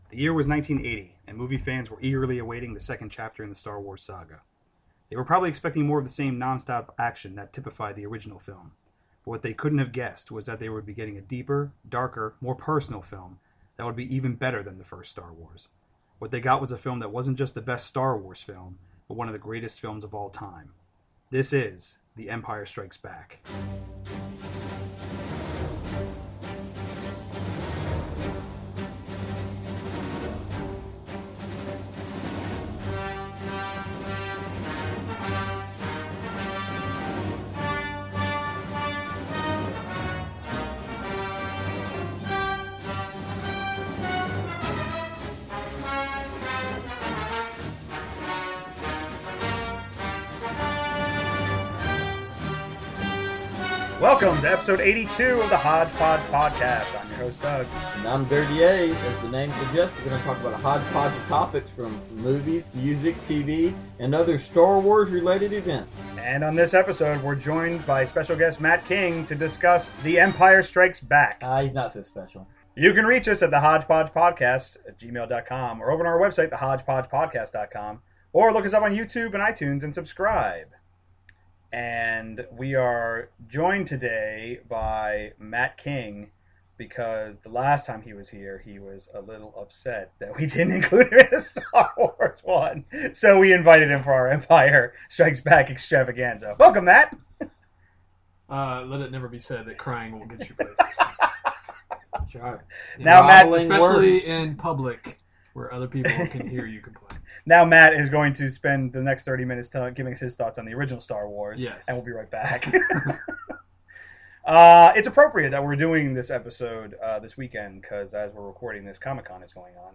three men unabashedly profess their love for a movie